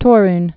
(tôrn, -nyə)